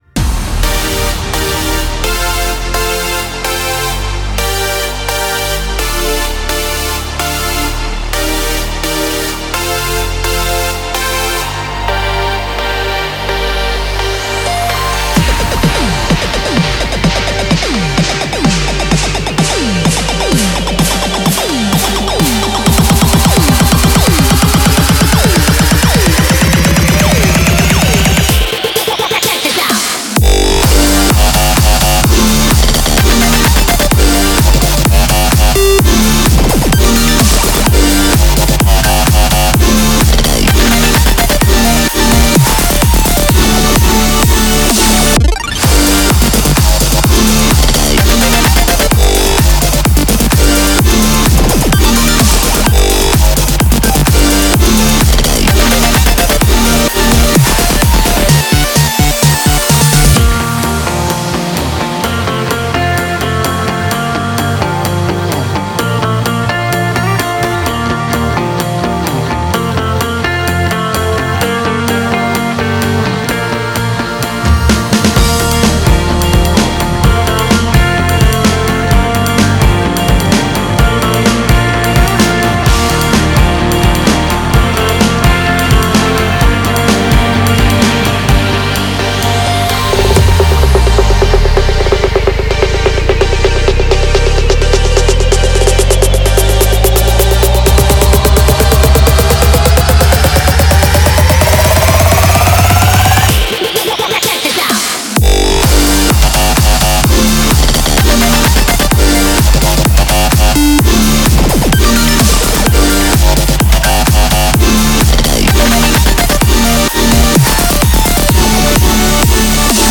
BPM128
Audio QualityMusic Cut